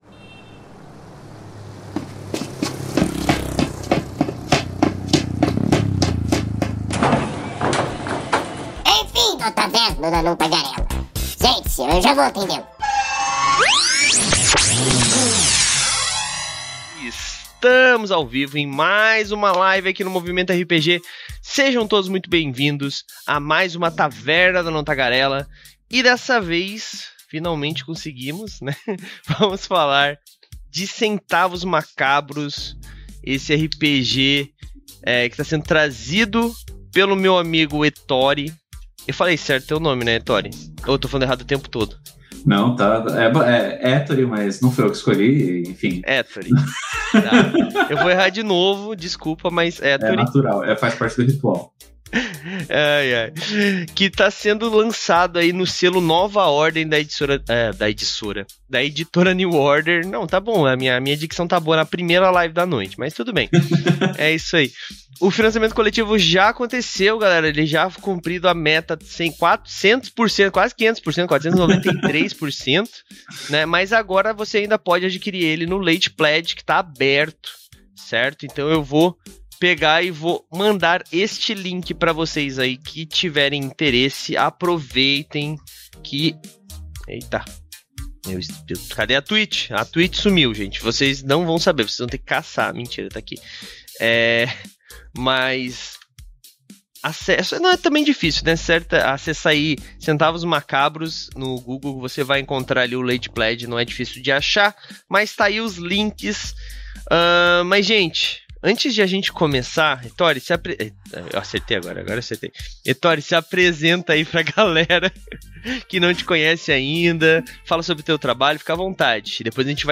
A Taverna do Anão Tagarela é uma iniciativa do site Movimento RPG, que vai ao ar ao vivo na Twitch toda a segunda-feira e posteriormente é convertida em Podcast. Com isso, pedimos que todos, inclusive vocês ouvintes, participem e nos mandem suas sugestões de temas para que por fim levemos ao ar em forma de debate.